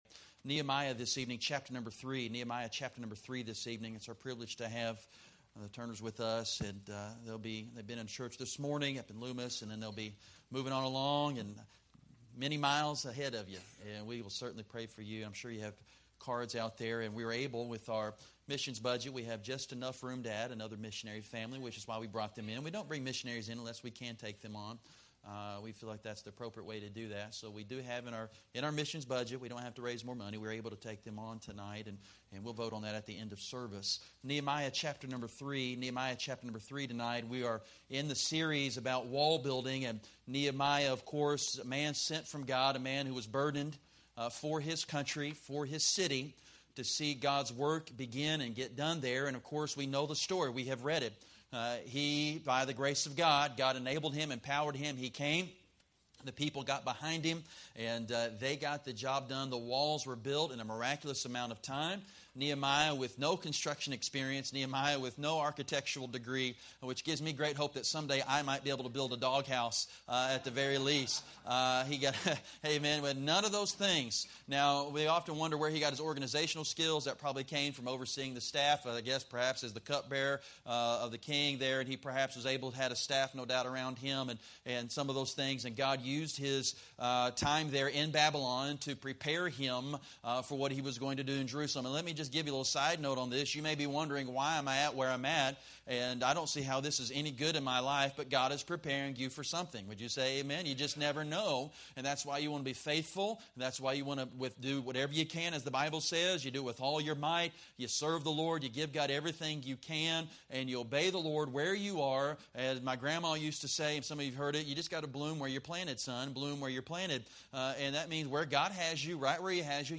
Sermons – Pioneer Baptist Church of Citrus Heights, CA